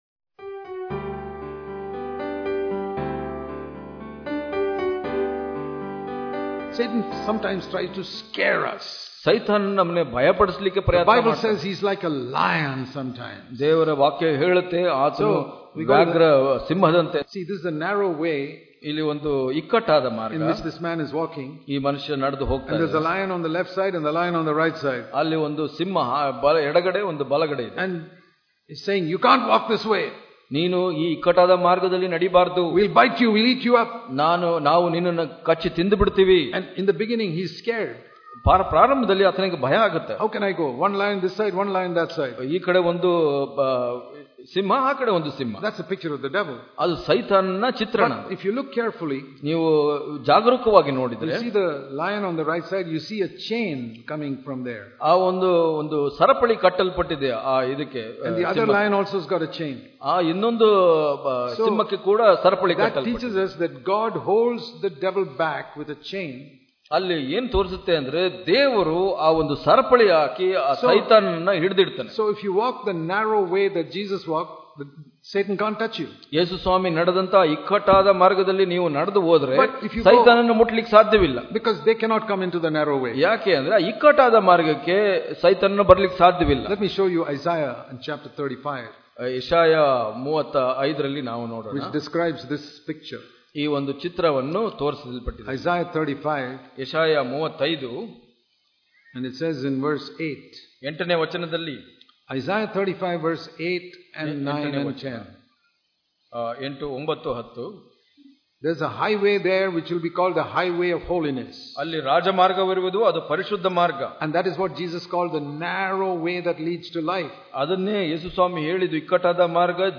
February 28 | Kannada Daily Devotion | Walking In The Narrow Way Daily Devotions